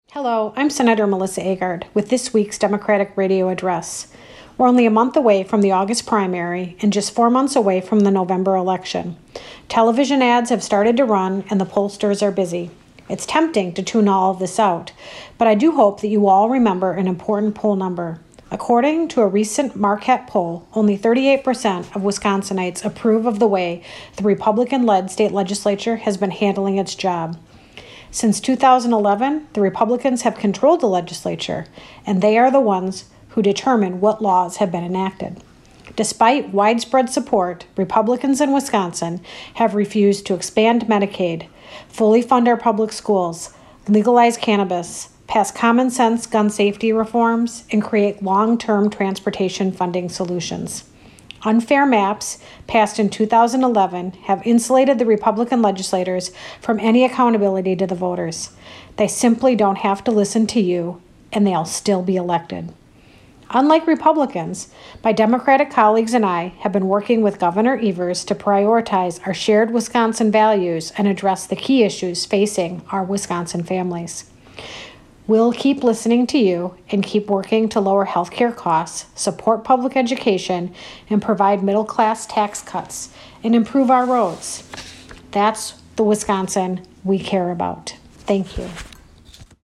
Weekly Dem address: Sen. Agard says Marquette poll shows majority Wisconsinites disapprove of the Republican-led state legislature - WisPolitics